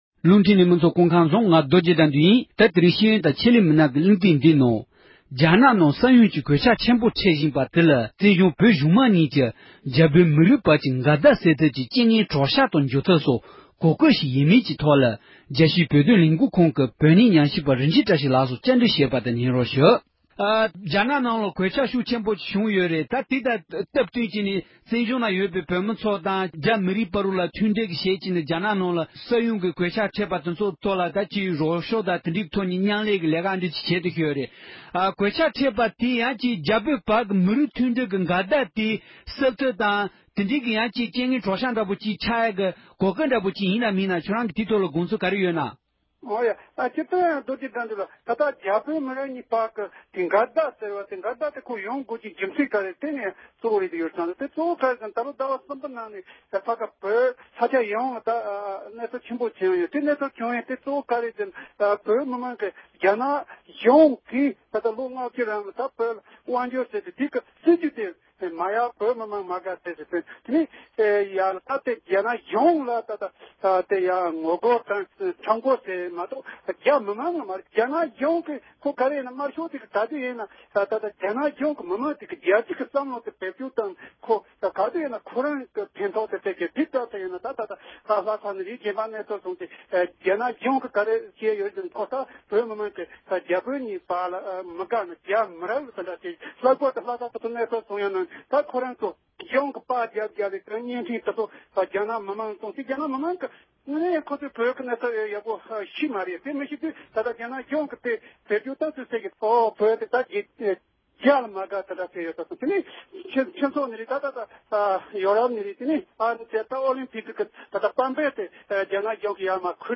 བཅའ་དྲི་བྱས་པ་དེ་གསན་རོགས་གནང༌༎